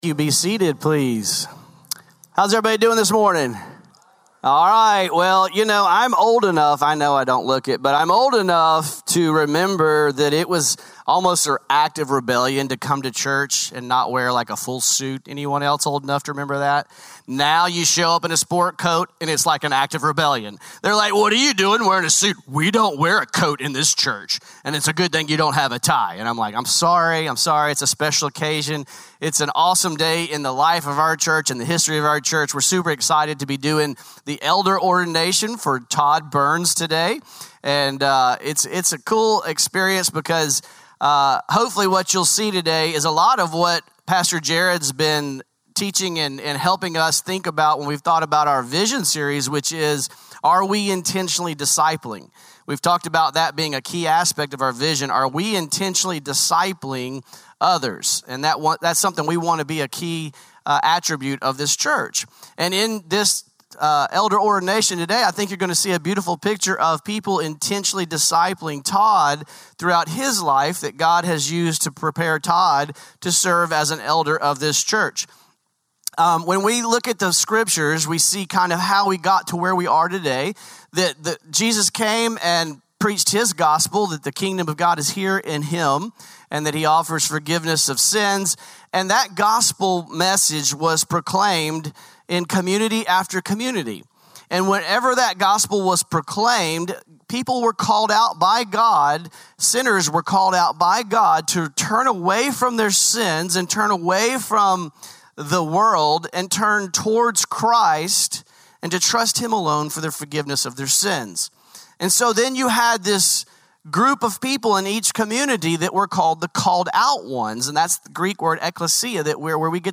Norris Ferry Sermons July 27